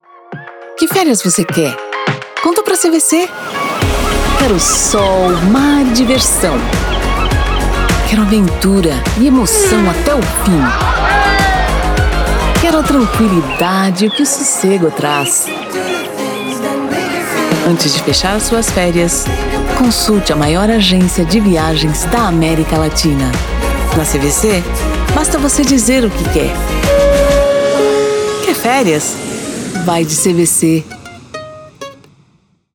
Locutora brasileira que tem uma voz firme , clara que transmite confiança e credibilidade. Outras características da voz: Amigável, maternal, conversada, Performa muito bem nos estilos voltados a Manifesto, institucional, corporativo.
Sprechprobe: Sonstiges (Muttersprache):
In my homestudio I am using the Microphone AKG P220 and Universal Audio Interface. The sound capture is done by REAPER tools and the verification through AKG52 headphones.